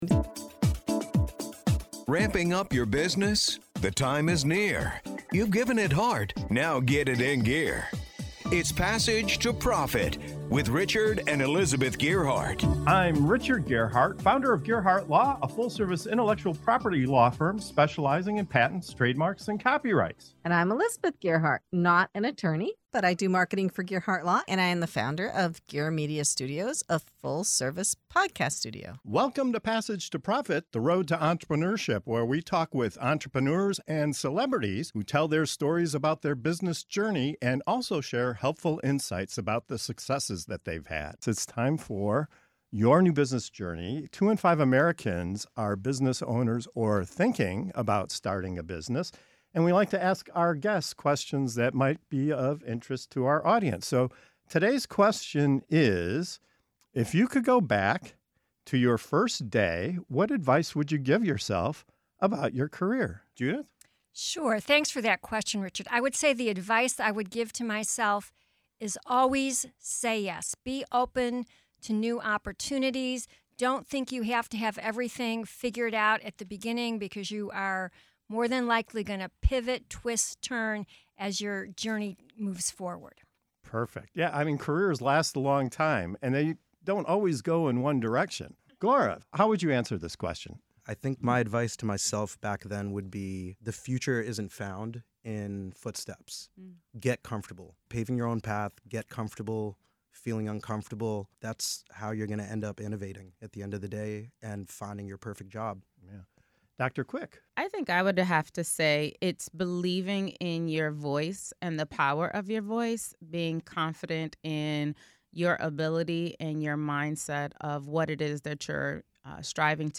In this inspiring segment of "Your New Business Journey" on Passage to Profit Show, our guests reflect on the advice they’d give their younger selves at the start of their careers—from saying “yes” to unexpected opportunities, to paving your own path, embracing discomfort, and trusting the power of your voice. You'll hear wisdom on staying true to your strengths, adapting through change, and remembering that success is more about the journey than the destination.